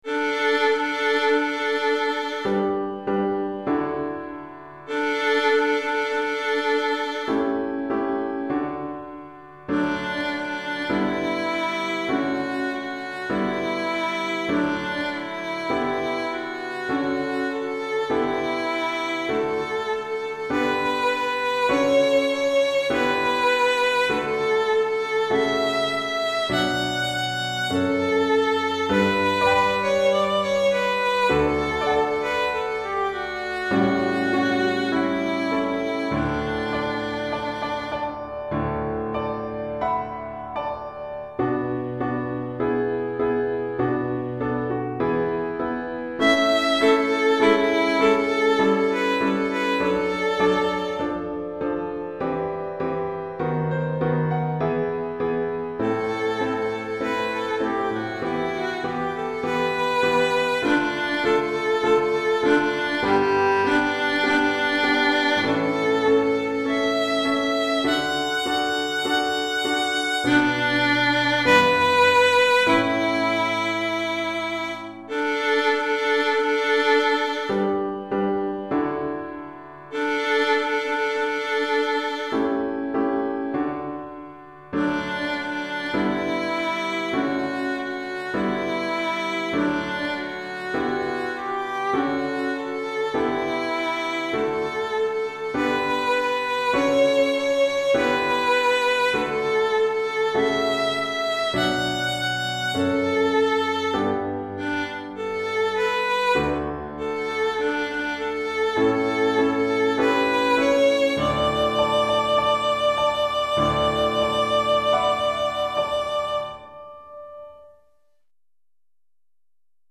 pour violon et piano
Violon et piano